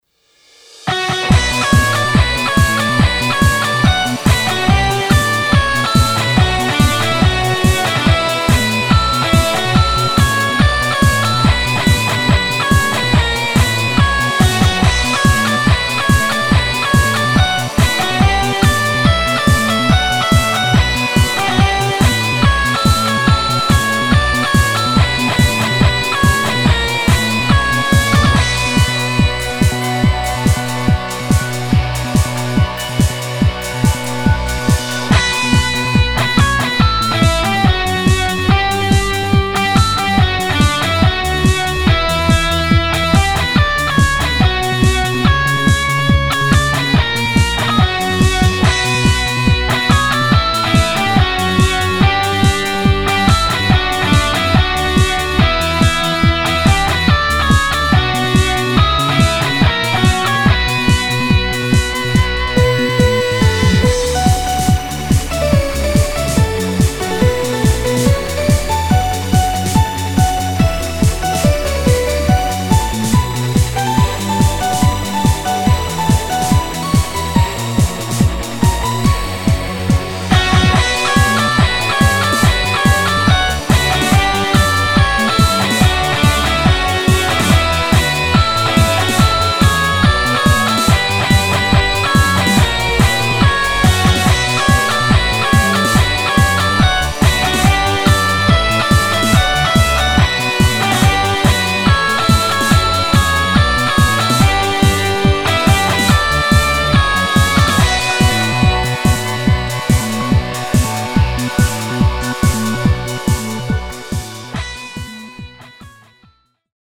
フリーBGM バトル・戦闘 4つ打ちサウンド
フェードアウト版のmp3を、こちらのページにて無料で配布しています。